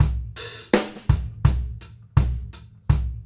GROOVE 160FL.wav